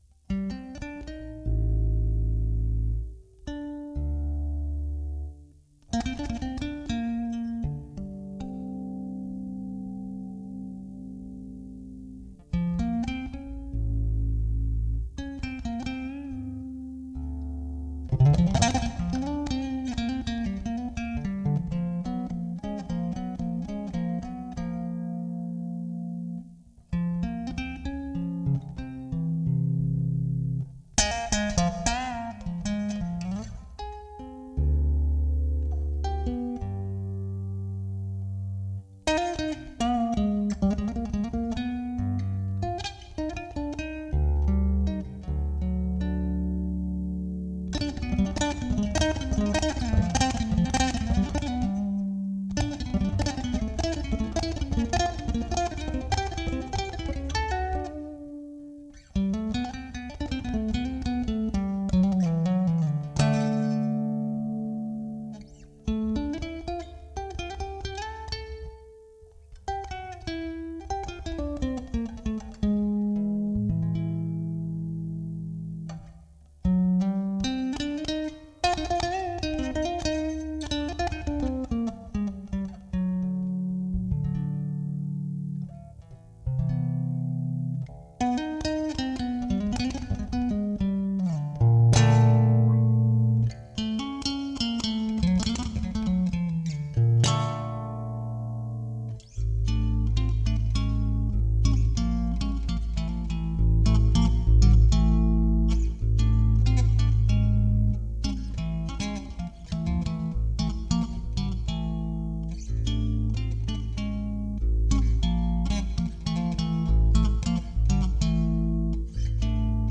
Alembic Club: SC African Blackwood Bass serie II/I
The bass is ADGC tuning, the sound is recorded with the direct output post eq. I use both pickups with some filters variations on the bridge pickup.